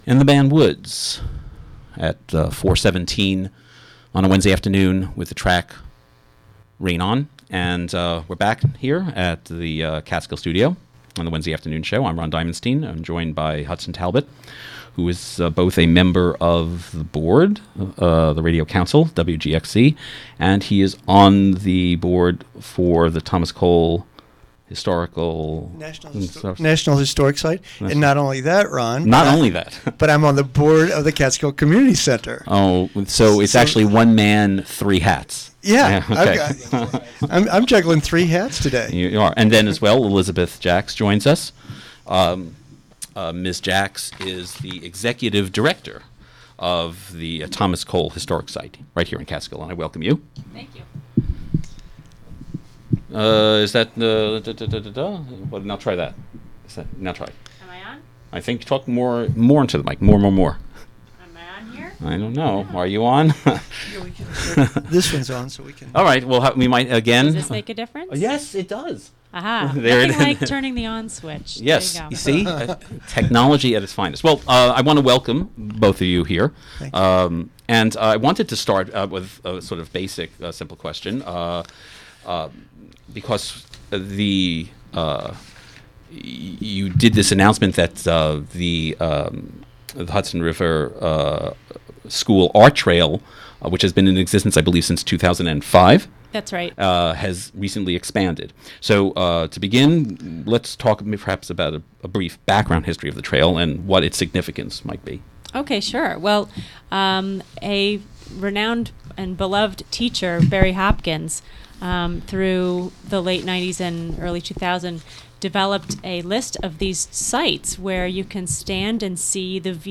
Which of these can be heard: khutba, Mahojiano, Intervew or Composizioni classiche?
Intervew